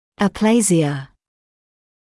[ə’pleɪzɪə][э’плэйзиэ]аплазия, недоразвитие, отсутствие развития